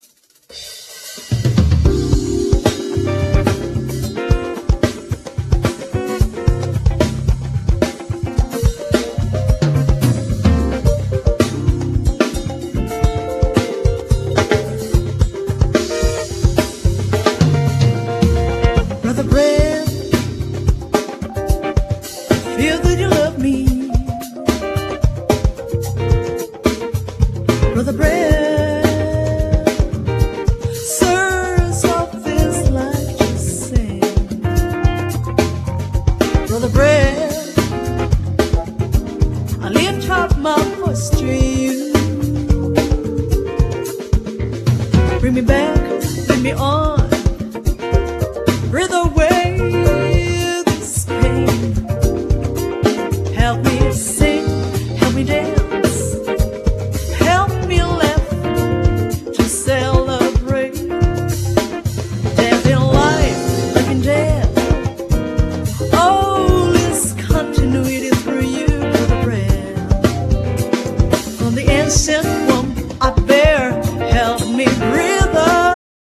Genere : Pop latin